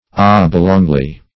oblongly - definition of oblongly - synonyms, pronunciation, spelling from Free Dictionary Search Result for " oblongly" : The Collaborative International Dictionary of English v.0.48: Oblongly \Ob"long*ly\, adv. In an oblong form.
oblongly.mp3